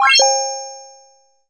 game_complete.mp3